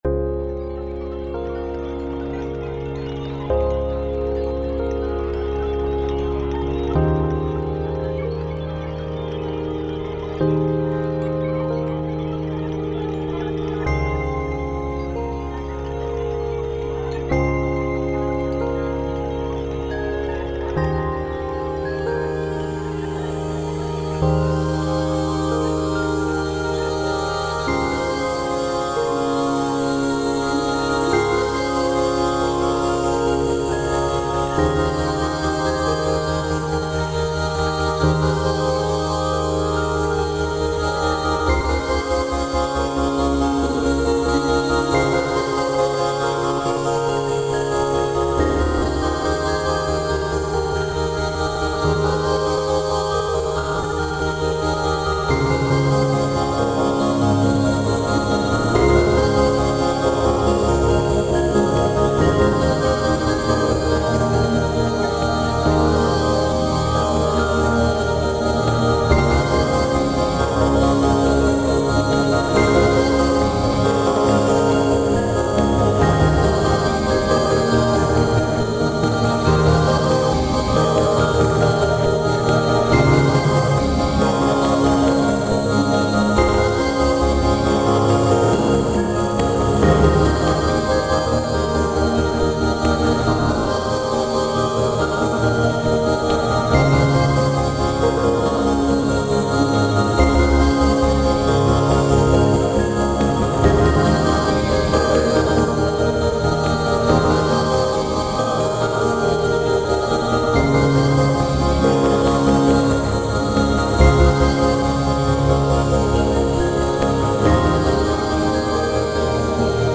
タグ: アンビエント 幻想的 音少なめ/シンプル コメント: 徐々に消えていくシーンをイメージしたBGM。